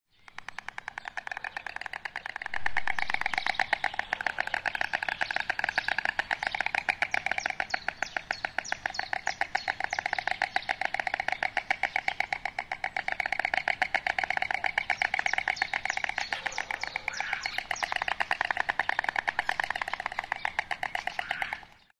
Ze klepperen wat af zeg, je zou denken dat ze het al druk genoeg hebben met babies bezorgen… Welke vogel hoor je hier?